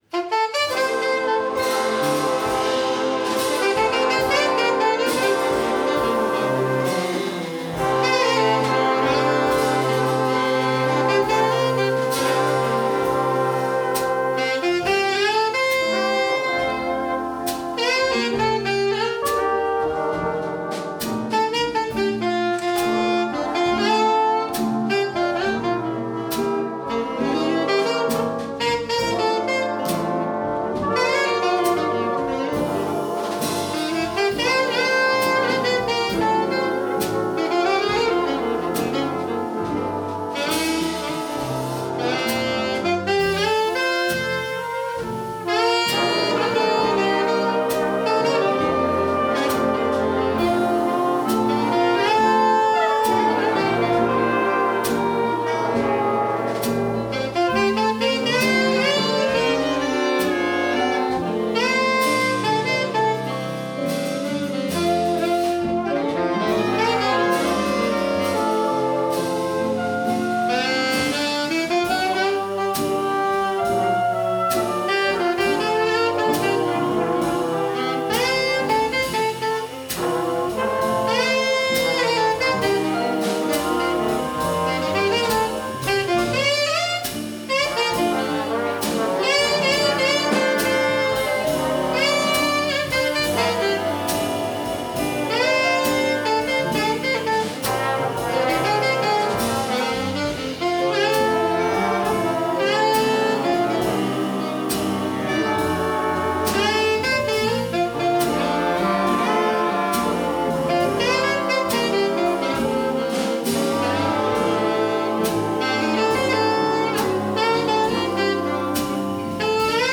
Udstyret er én digital stereo mikrofon, ikke en studieoptagelse !